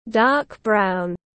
Màu nâu đậm tiếng anh gọi là dark brown, phiên âm tiếng anh đọc là /dɑ:k braʊn/.
Dark brown /dɑ:k braʊn/